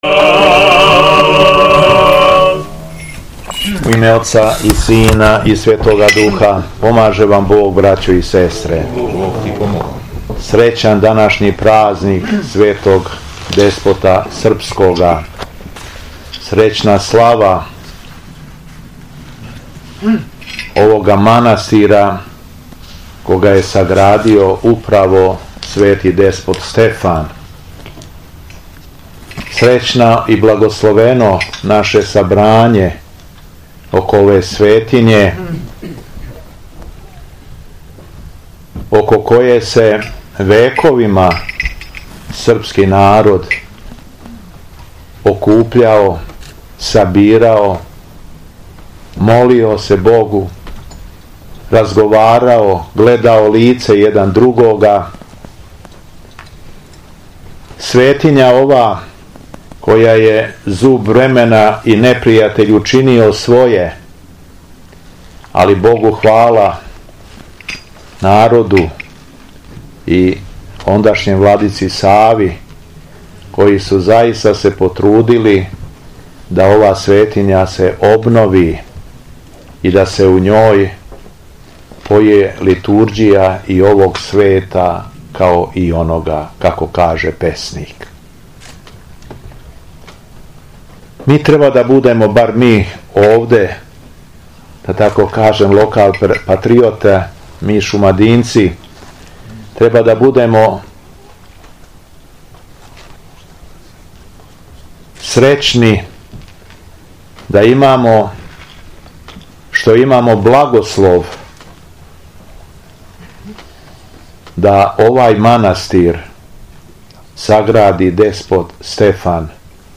Беседа Његовог Високопреосвештенства Митрополита шумадијског г. Јована
Након прочитаног јеванђељског одломка, у својој Богонадахнутој беседи наш Митрополит је рекао: